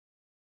sounds / monsters / rat / idle_0.ogg